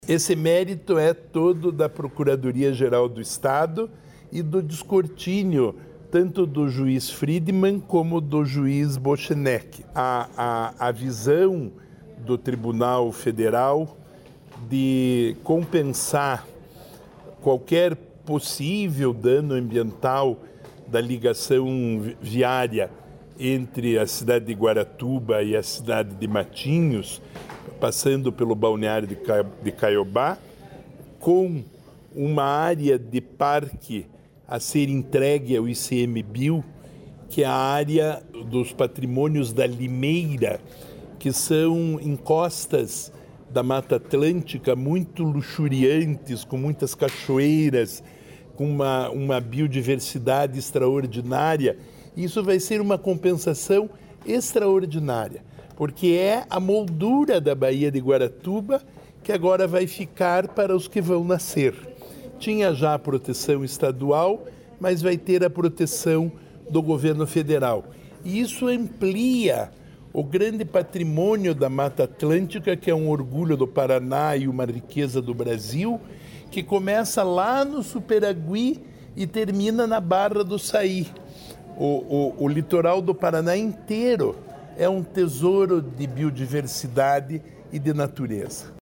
Sonora do secretário do Desenvolvimento Sustentável, Rafael Greca, sobre legalidade ambiental da Ponte de Guaratuba